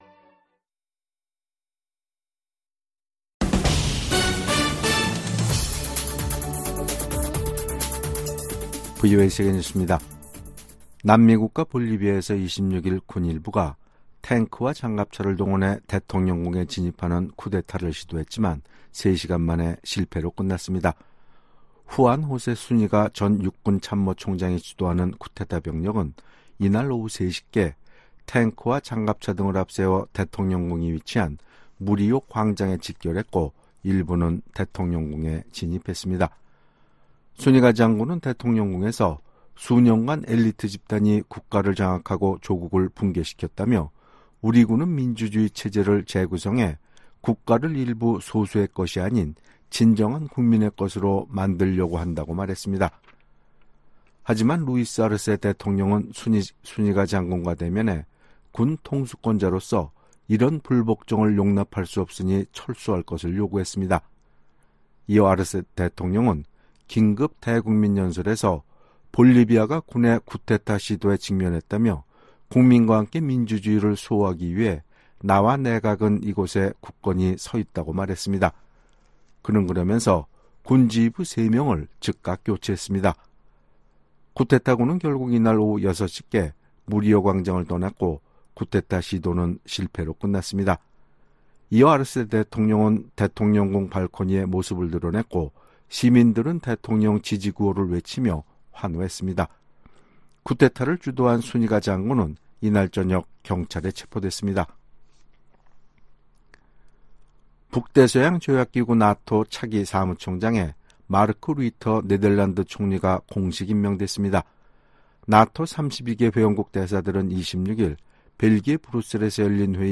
VOA 한국어 간판 뉴스 프로그램 '뉴스 투데이', 2024년 6월 27일 2부 방송입니다. 미국 정부는 러시아의 우크라이나 점령지로 북한 노동자가 파견될 가능성에 반대 입장을 분명히 했습니다. 존 허브스트 전 우크라이나 주재 미국 대사는 북한이 우크라이나에 군대를 파병할 가능성은 높지 않다고 말했습니다. 북한은 처음으로 진행한 다탄두 미사일 시험발사가 성공적이었다고 주장했습니다.